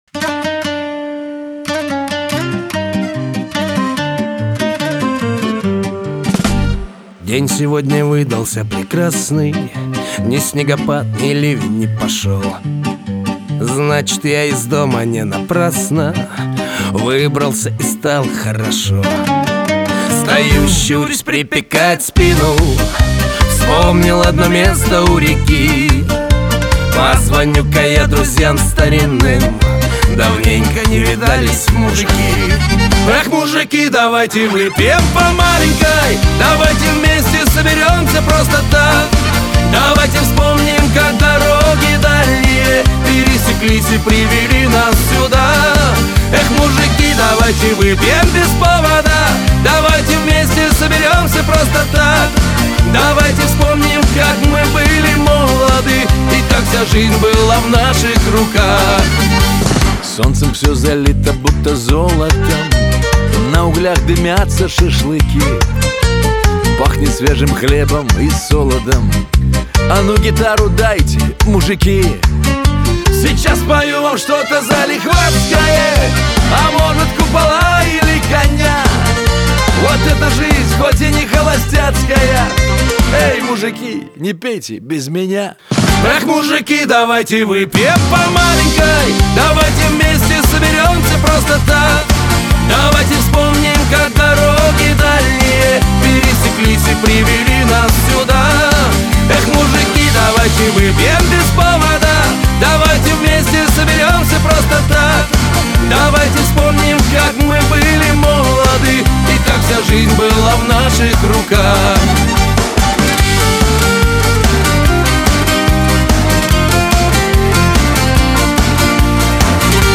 диско
Шансон , дуэт
эстрада